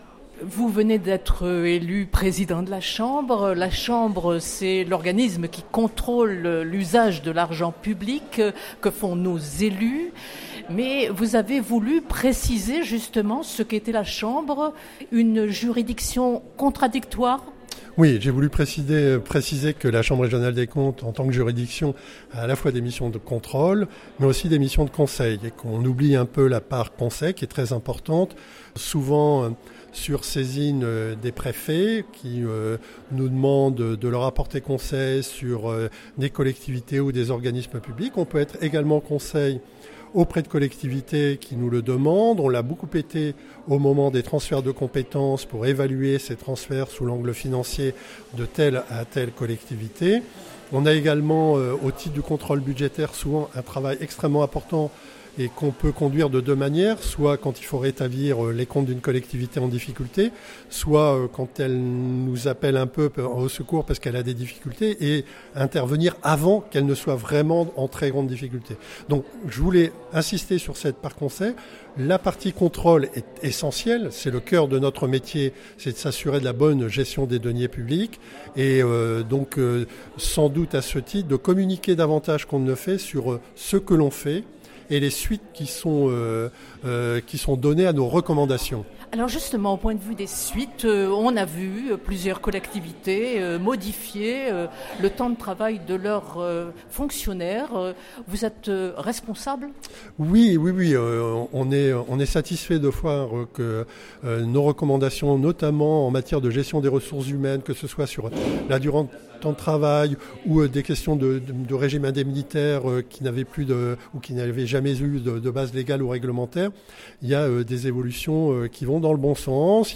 Entretien.
nacer_meddah_presentation_a_la_presse_3_10_2018.mp3